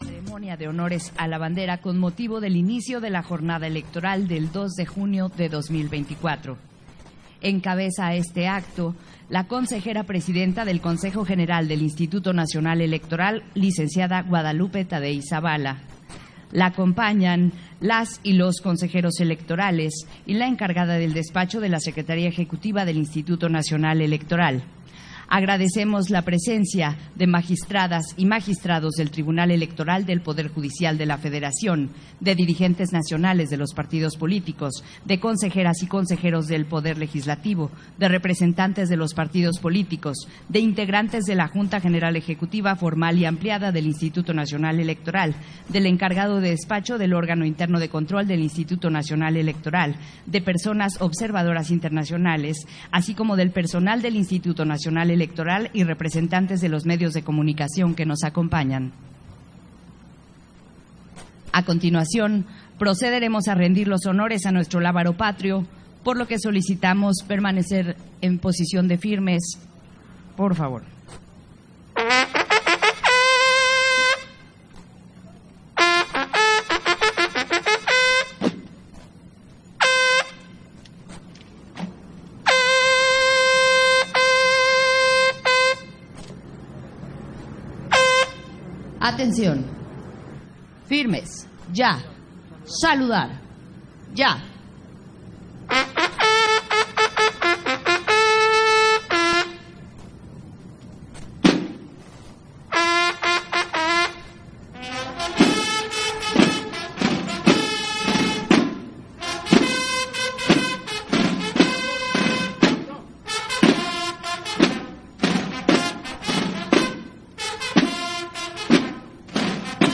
020624_AUDIO_CEREMONIA-DE-HONORES-A-LA-BANDERA-CON-MOTIVO-DEL-INICIO-DE-LA-JORNADA-ELECTORAL
Versión estenográfica de la ceremonia de Honores a la Bandera con motivo del inició de la jornada electoral del 2 de junio